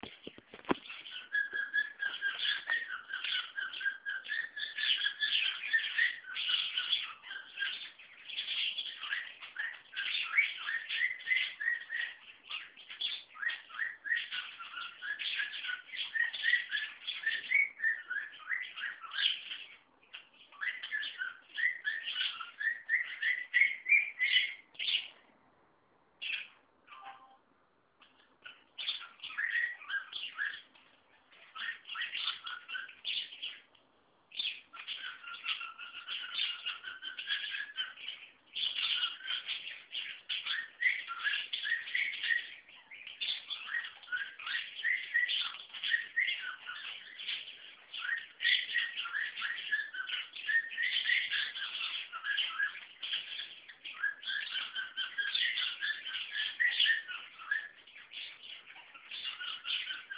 Balzende Hähne
Nymphensittichhähne können sehr unterschiedliche Gesänge entwickeln.
singenderhahn3.wav